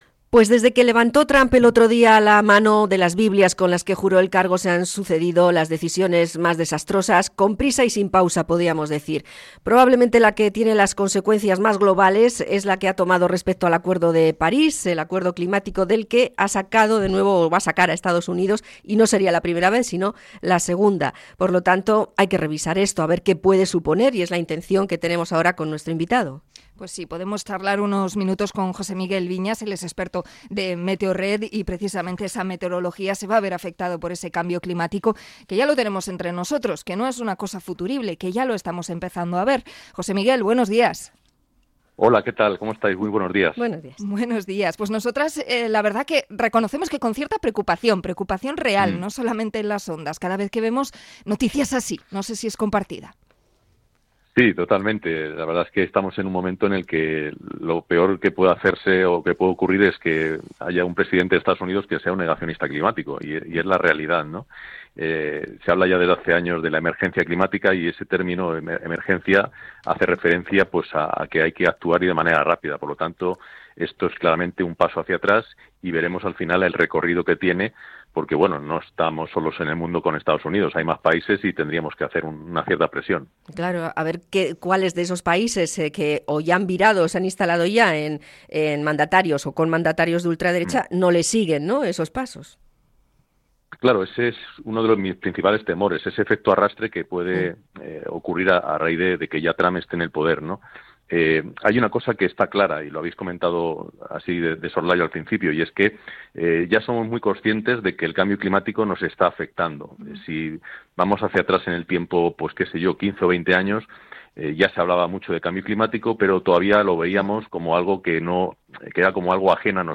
Entrevista a meteorólogo por el negacionismo climático de Trump